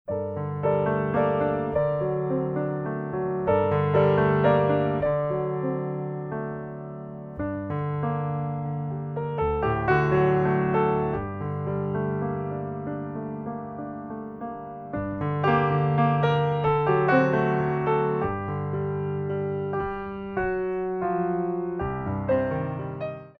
By Pianist & Ballet Accompanist
Piano selections include:
Port de Bras